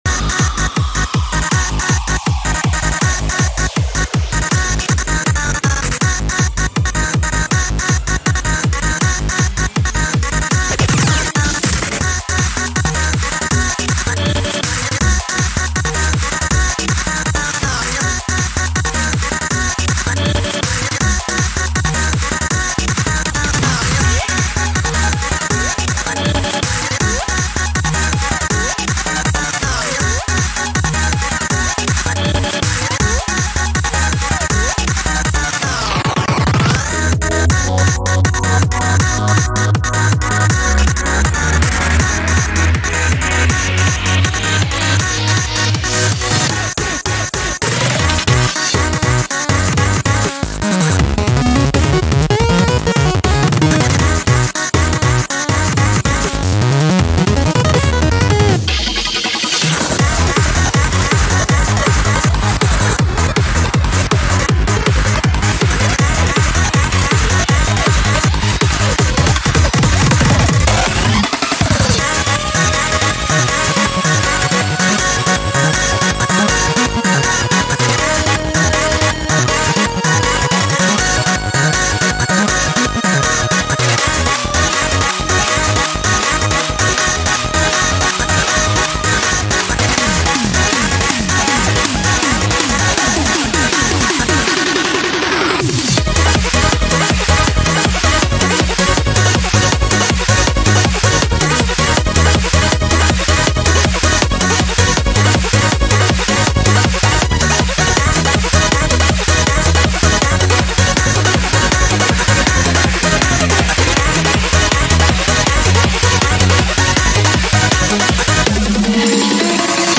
BPM160
Audio QualityCut From Video